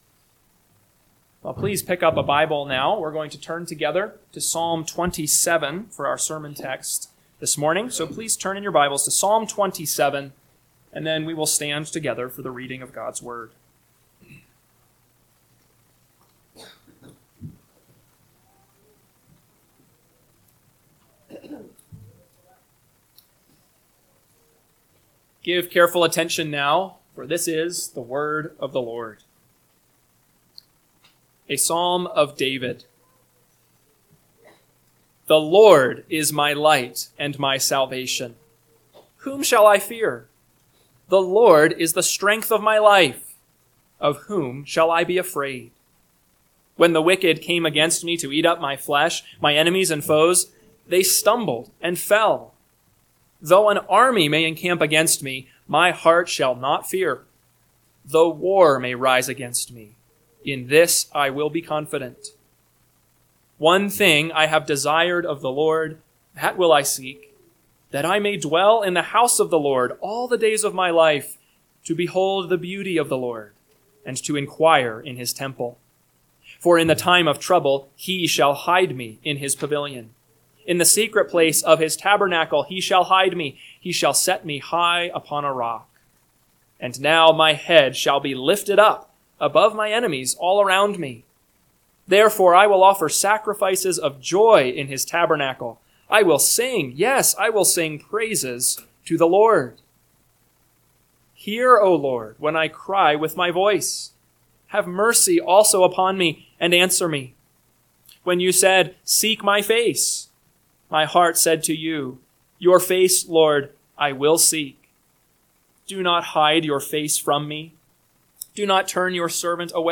AM Sermon – 8/3/2025 – Psalm 27 – Northwoods Sermons